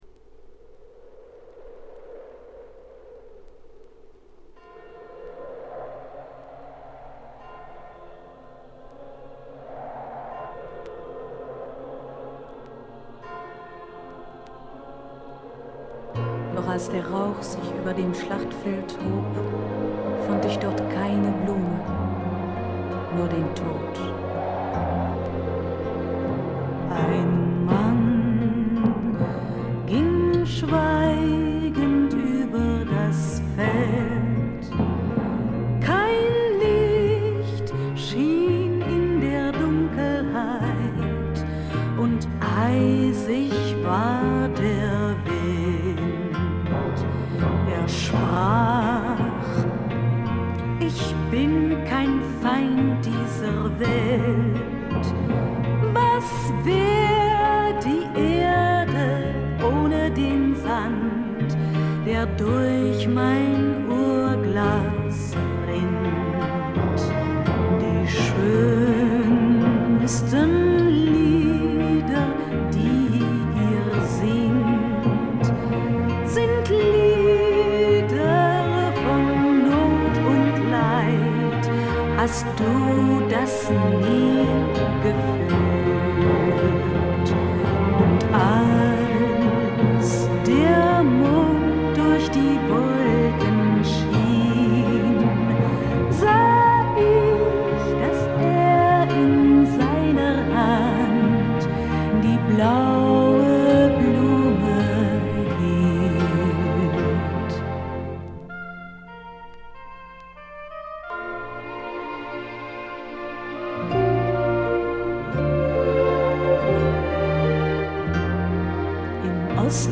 部分試聴ですが、わずかなノイズ感のみ。
試聴曲は現品からの取り込み音源です。